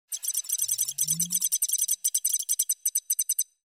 机器人数字加载音效免费下载素材
SFX音效